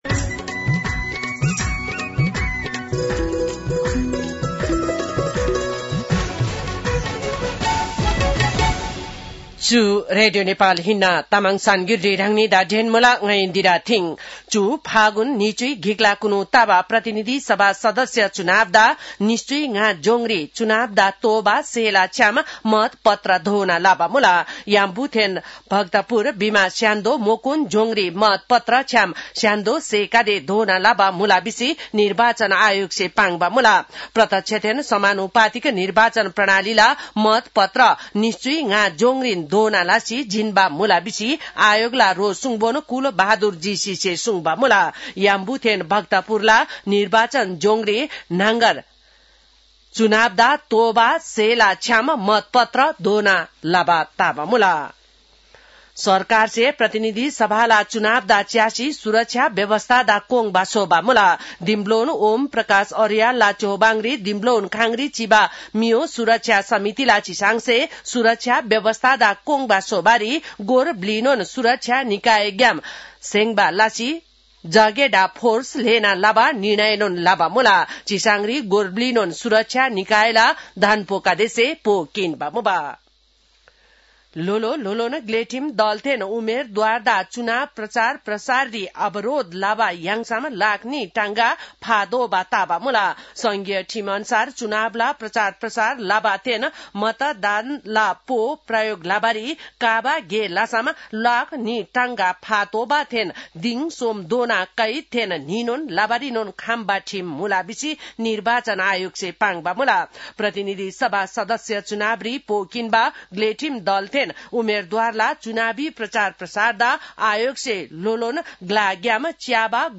An online outlet of Nepal's national radio broadcaster
तामाङ भाषाको समाचार : १३ फागुन , २०८२